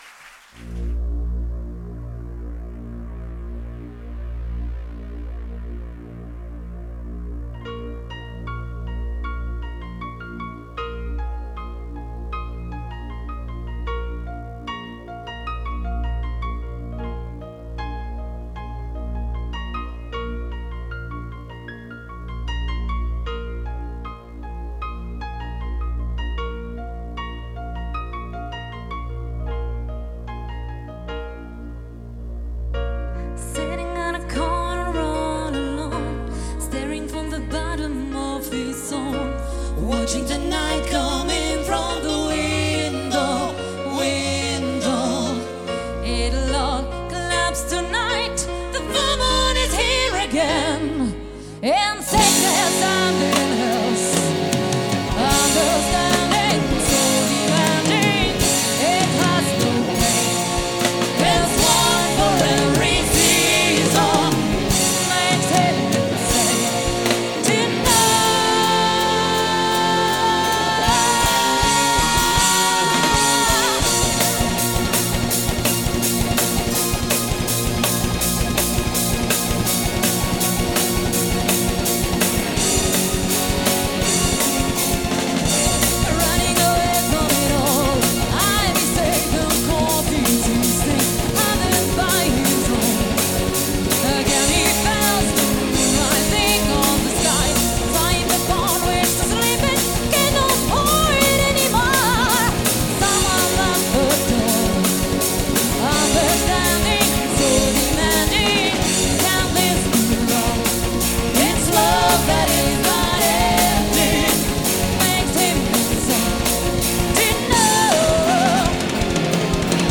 Ladiesnite 2010